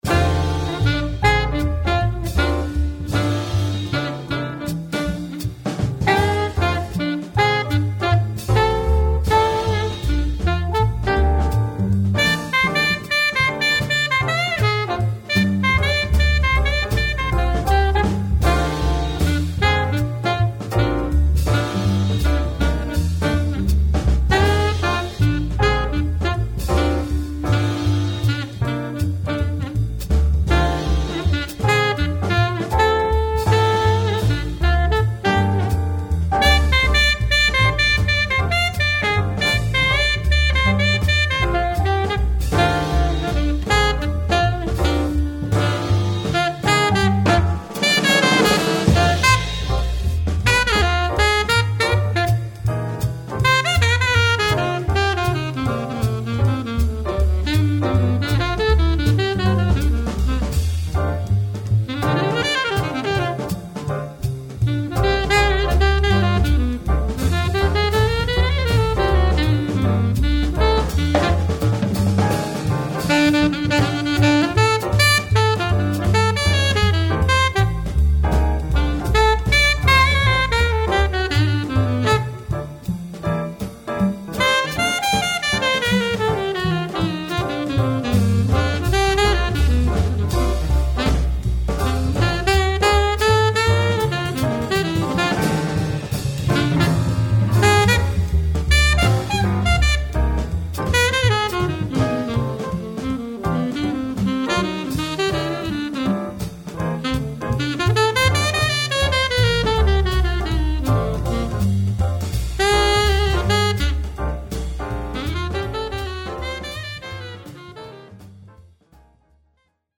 piano
bass
drums
alto saxophone